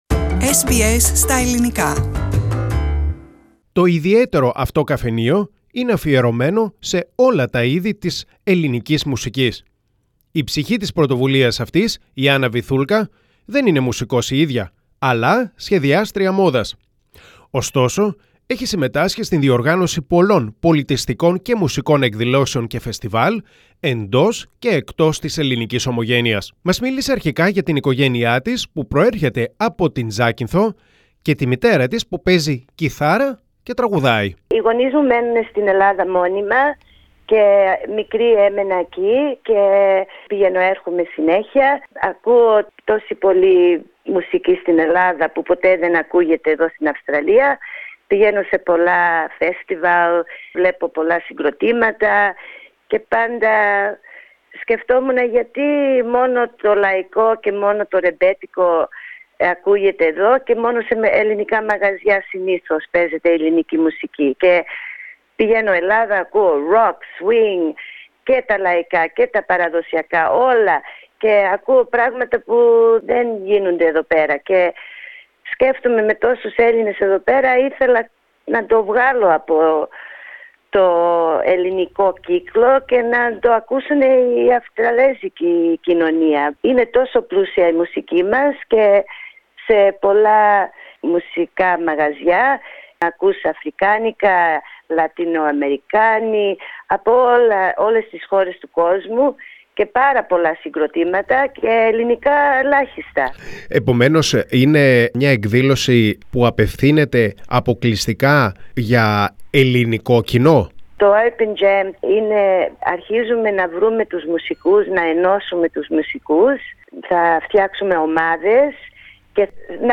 που ζει στην Αυστραλία τα τελευταία χρόνια μίλησαν στο Ελληνικό Πρόγραμμα.